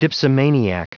Prononciation du mot dipsomaniac en anglais (fichier audio)
Prononciation du mot : dipsomaniac